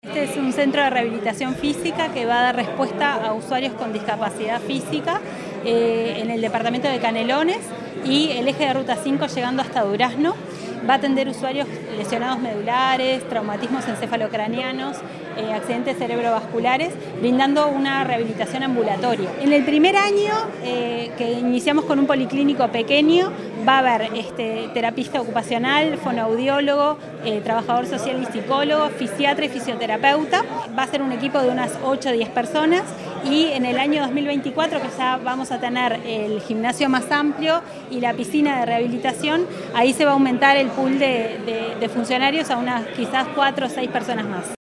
Presentación del avance de obras del Centro de Rehabilitación del Sur